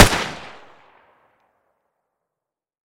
Index of /server/sound/weapons/cw_ar15
fire_longbarrel_suppressed.wav